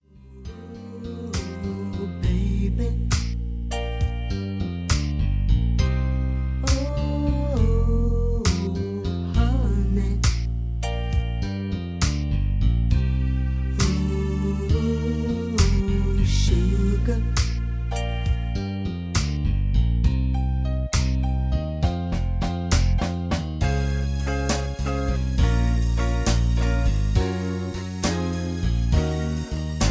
karaoke mp3s , backing tracks